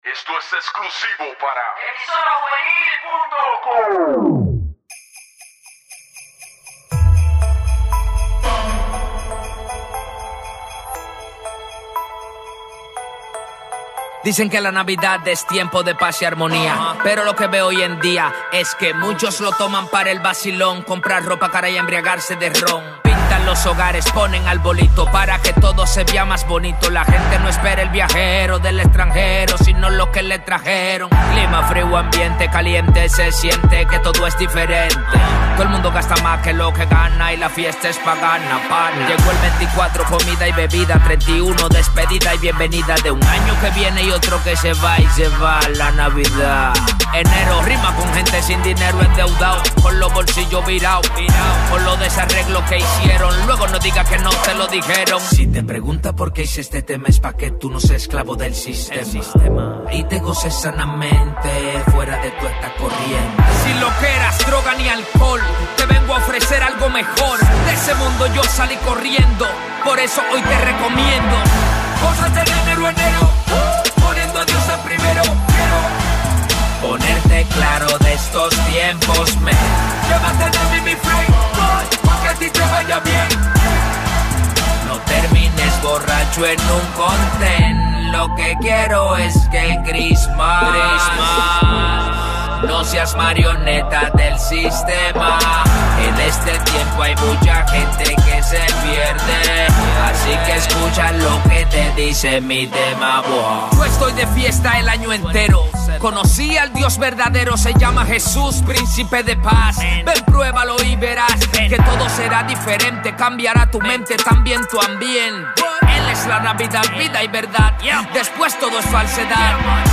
Música Cristiana
artista urbano